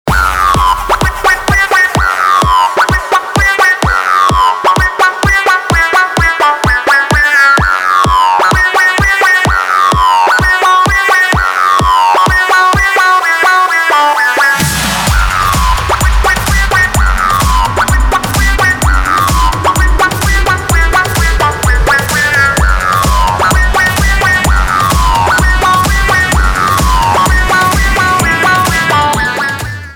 • Качество: 320, Stereo
громкие
dance
EDM
электронная музыка
без слов
club
electro house